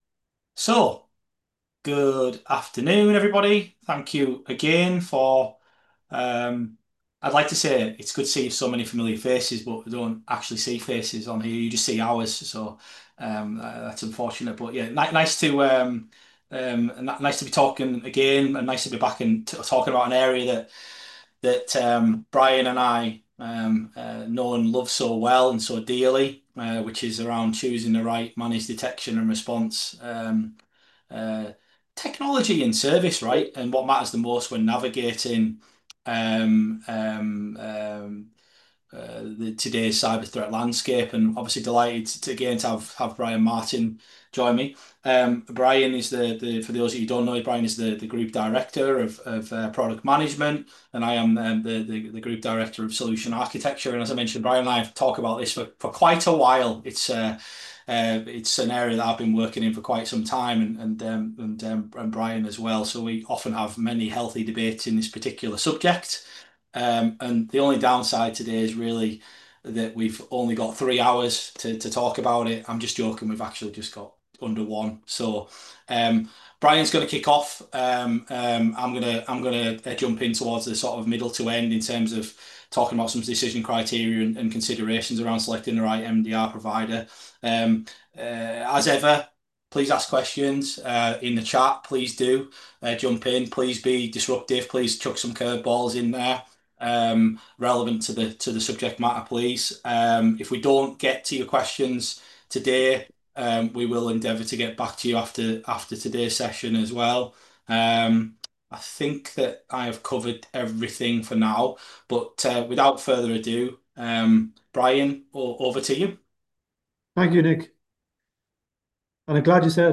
Integrity360 Webinar | On-Demand | Choosing the right MDR
With MDR services growing in popularity, how do you choose the right one for your organisation? In this webinar, Integrity360’s experts will cut through the noise and explore the critical factors to consider when selecting an MDR provider.